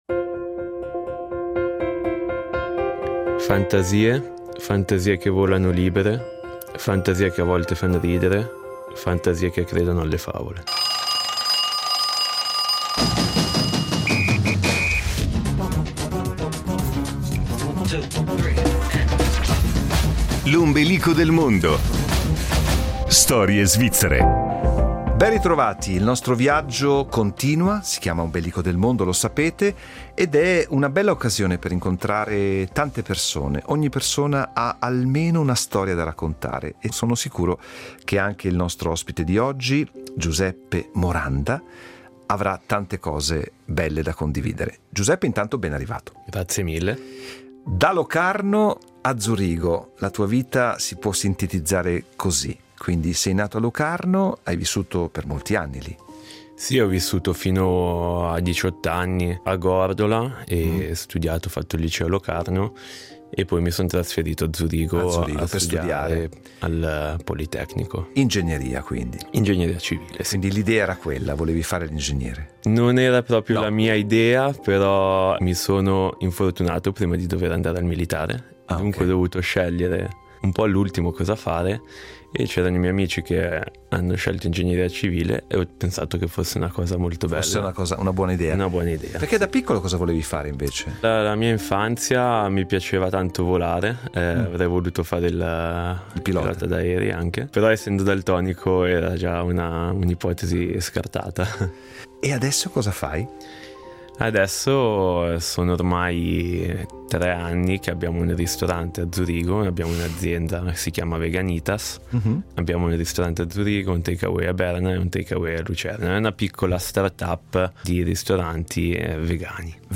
La sua scelta musicale ha privilegiato una canzone di Vasco Rossi.